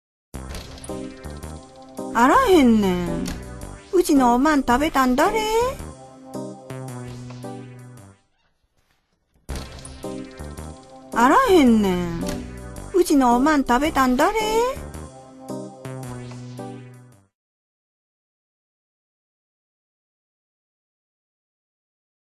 • 読み上げ
市田ひろみ 語り●市田ひろみ